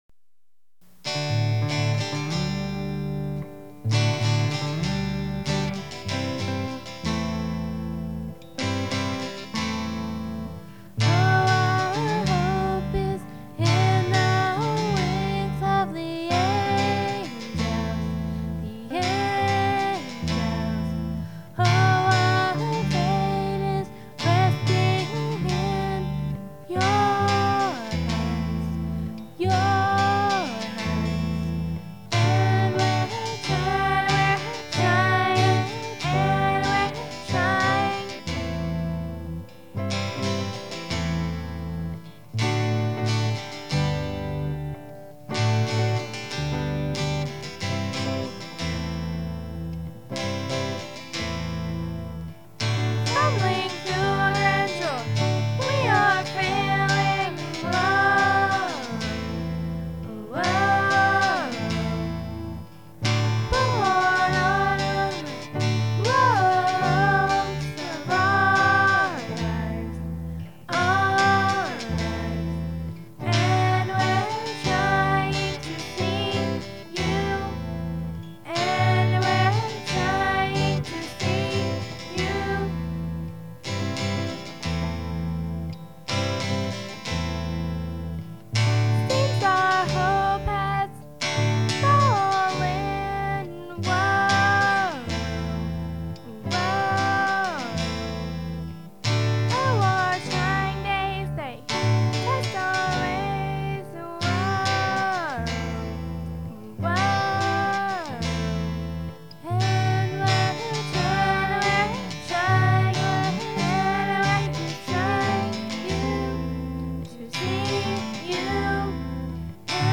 this was on of my first songs ever recorded. didn't turn out half bad - just mostly bad. recorded in early '98 i played rhythm, lead, and bass guitar. while my sister had her friend sang vocals.